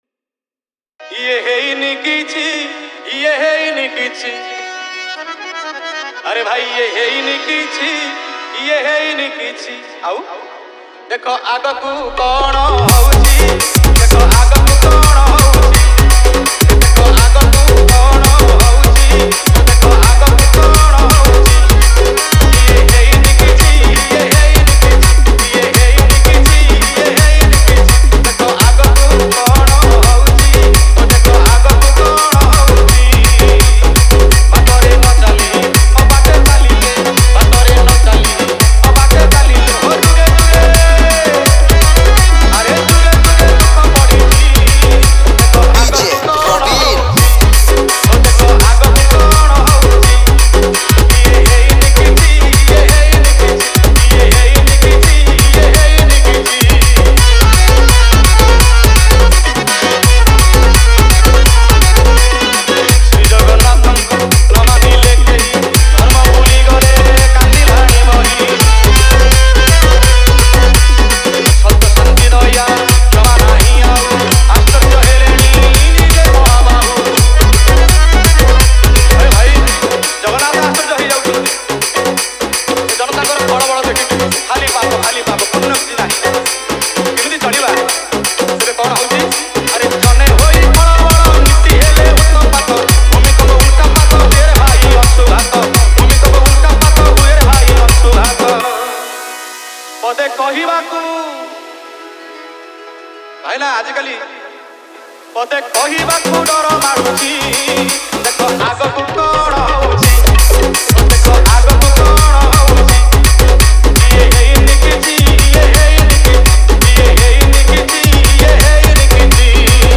Bhajan Dj Song Collection 2021